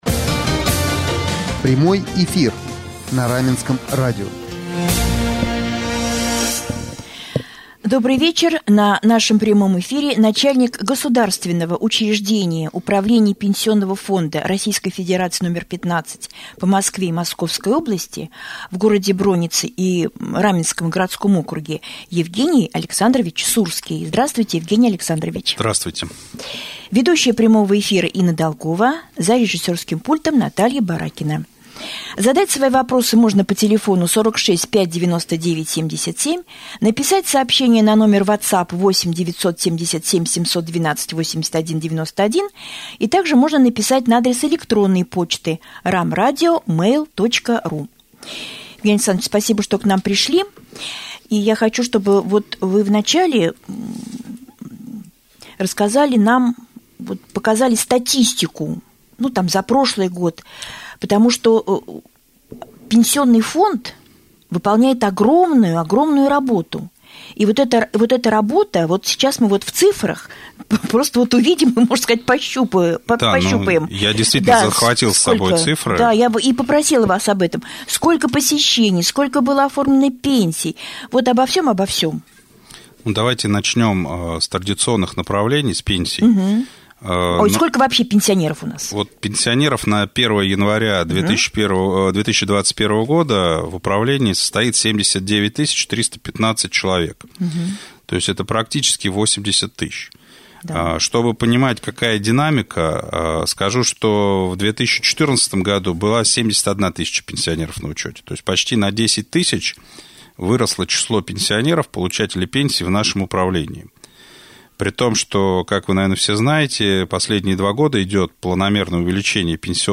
Во время беседы в прямом эфире обсудили вопросы: